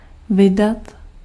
Ääntäminen
IPA : /ɹɪˈliːs/